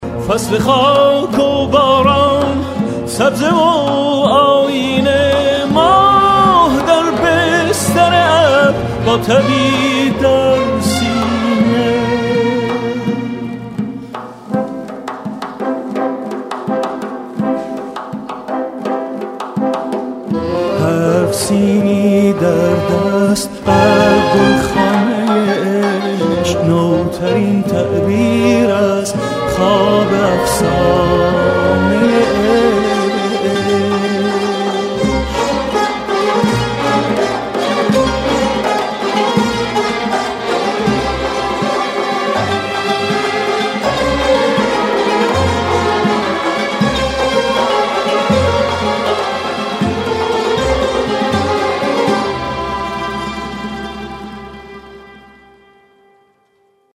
زنگ خور با کلام موبایل باکلام